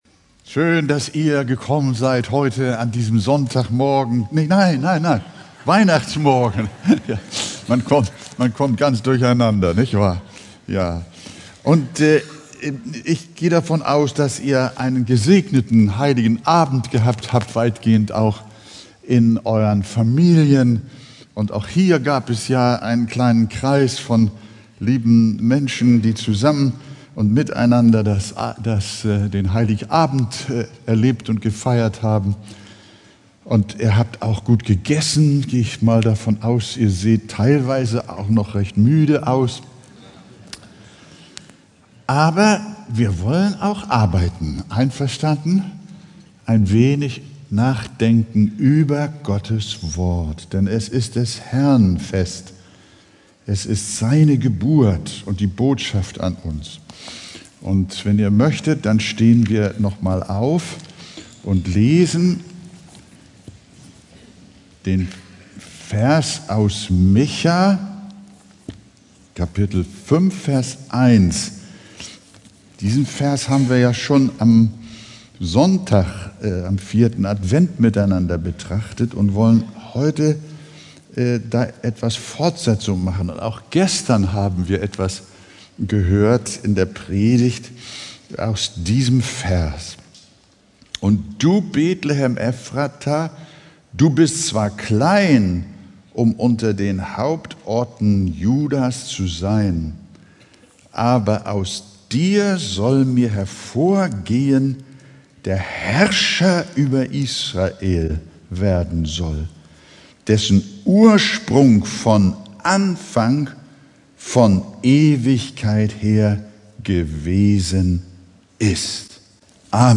Weihnachtsgottesdienst | Micha 5,1